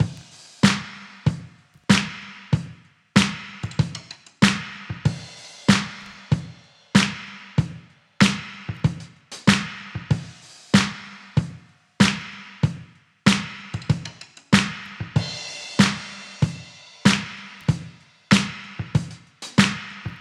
• 95 Bpm Breakbeat E Key.wav
Free breakbeat sample - kick tuned to the E note. Loudest frequency: 1482Hz
95-bpm-breakbeat-e-key-4NC.wav